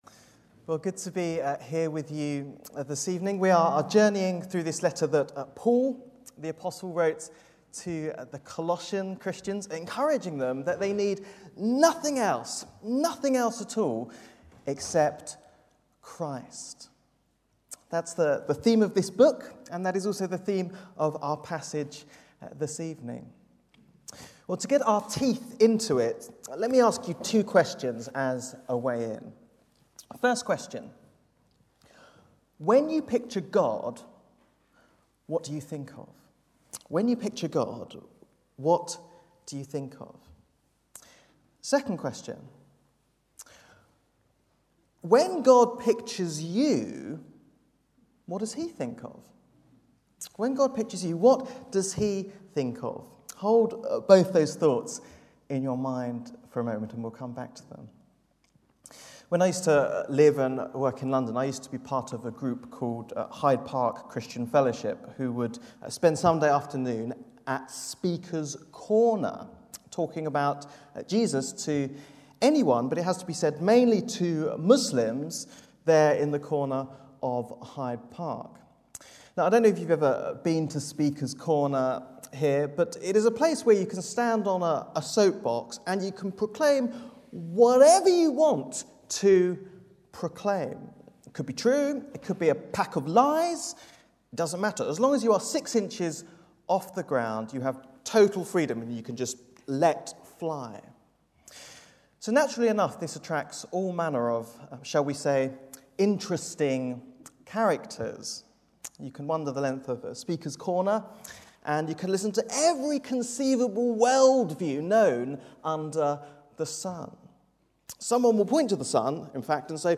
Bible Text: Colossians 1:15-22 | Preacher